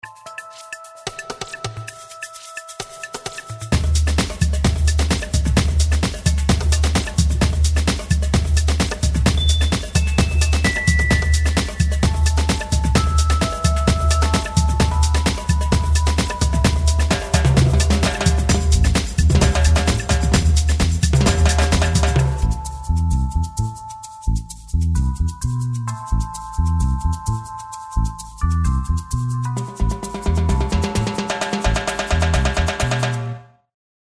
Moderato [100-110] amour - percussions - fete - joie - danse